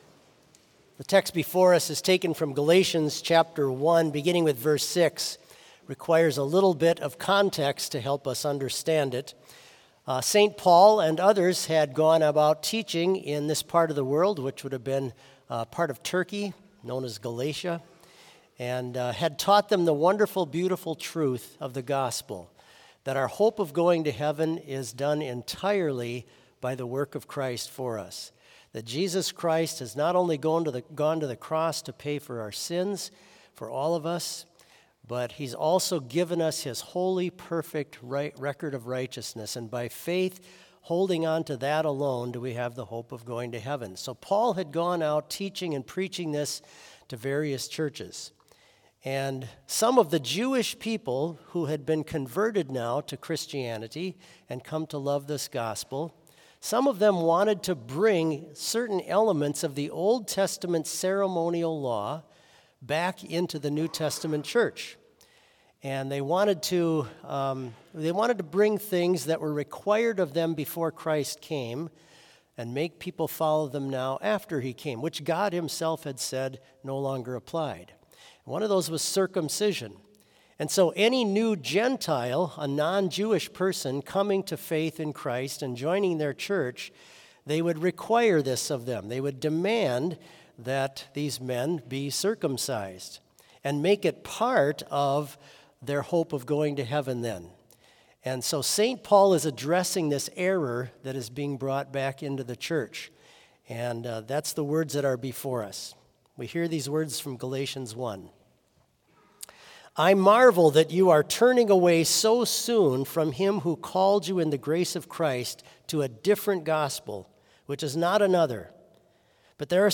Complete service audio for Chapel - Friday, October 25, 2024